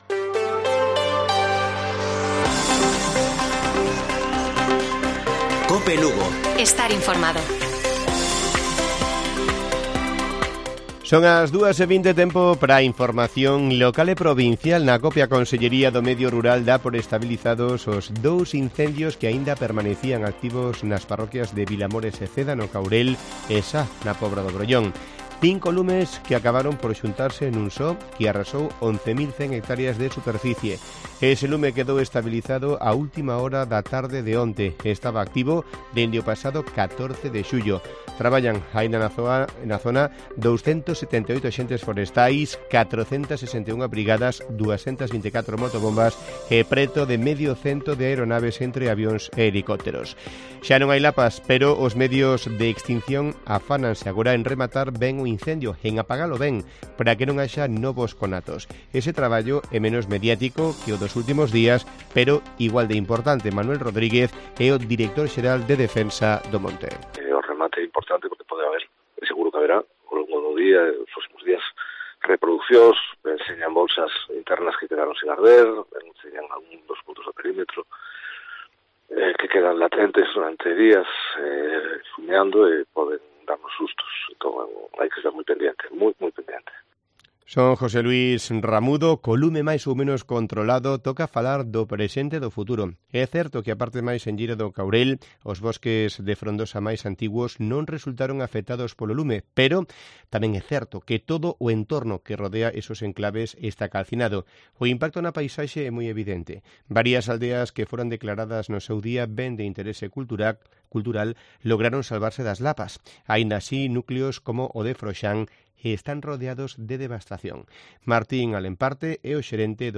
Informativo Mediodía de Cope Lugo. 26 DE JULIO. 14:20 horas